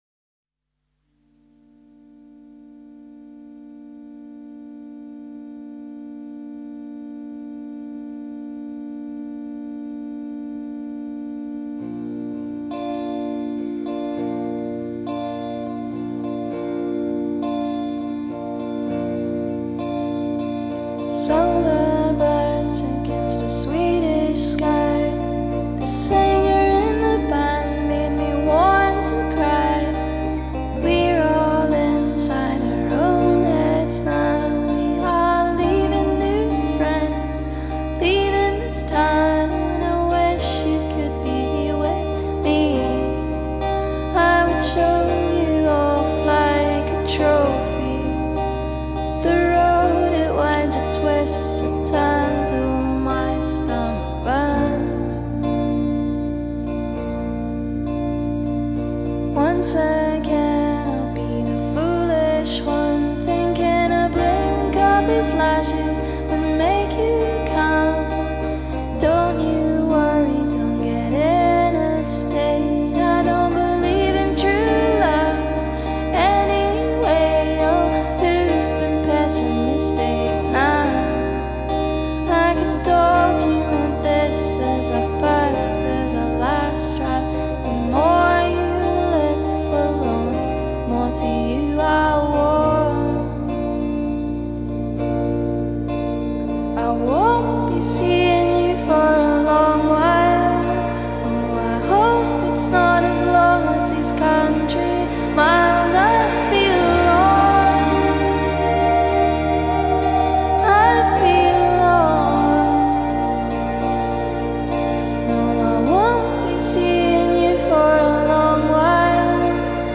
Genre: pop / indie